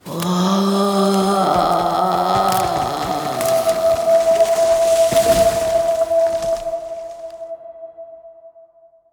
ElmDeathScream.ogg